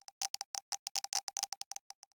Minecraft Version Minecraft Version latest Latest Release | Latest Snapshot latest / assets / minecraft / sounds / ambient / nether / basalt_deltas / click1.ogg Compare With Compare With Latest Release | Latest Snapshot
click1.ogg